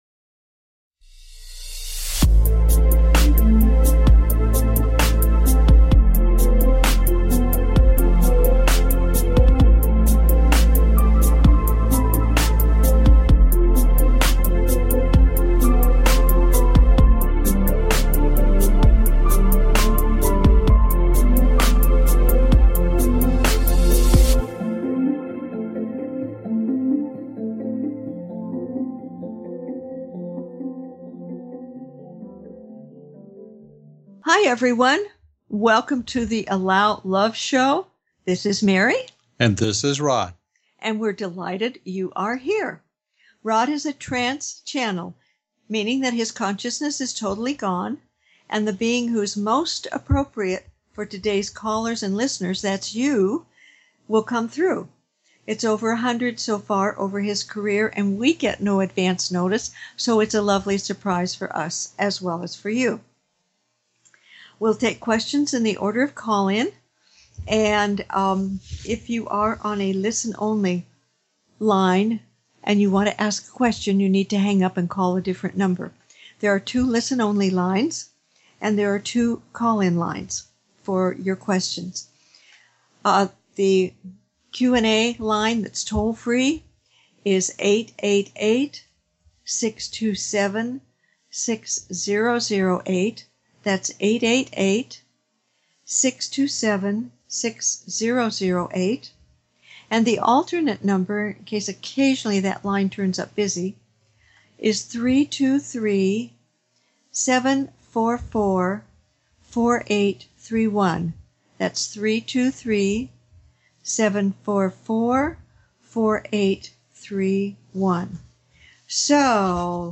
Talk Show
Their purpose is to provide answers to callers’ questions and to facilitate advice as callers request.